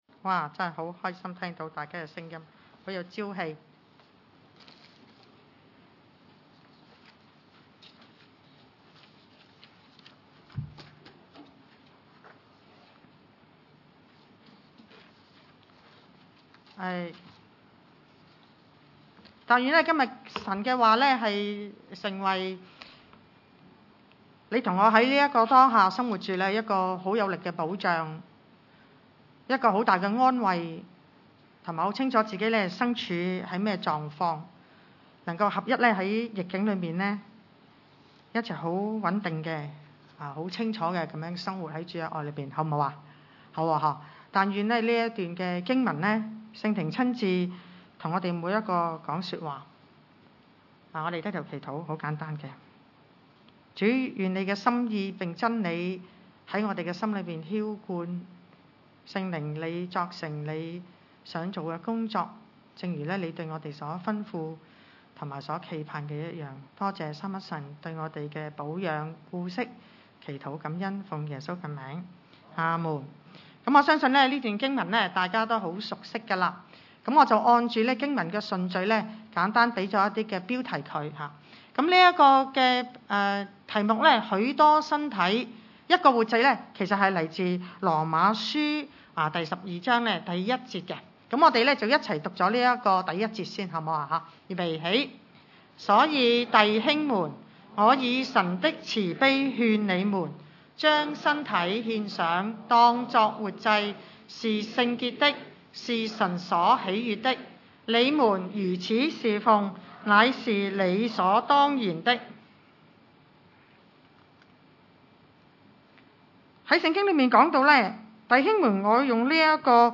羅12:1-5 崇拜類別: 主日午堂崇拜 所以弟兄們，我以神的慈悲勸你們，將身體獻上，當作活祭，是聖潔的，是神所喜悅的；你們如此事奉乃是理所當然的。